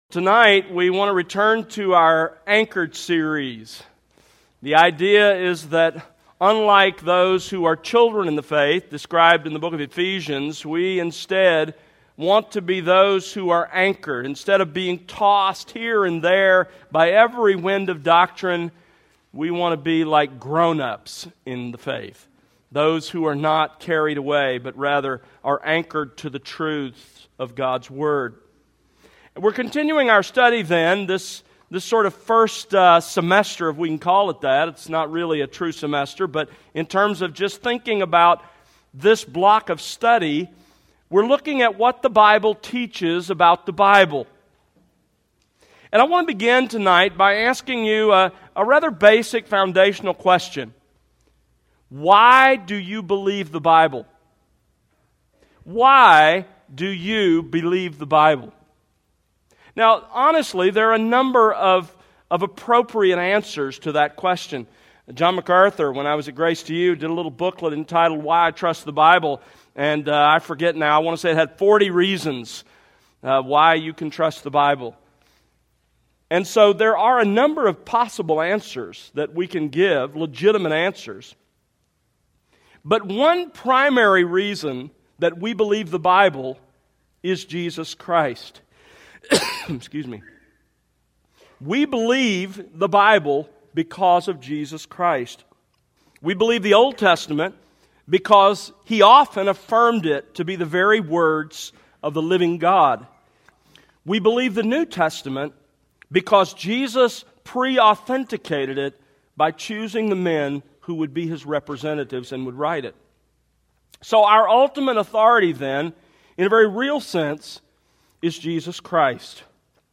Sermons That Exalt Christ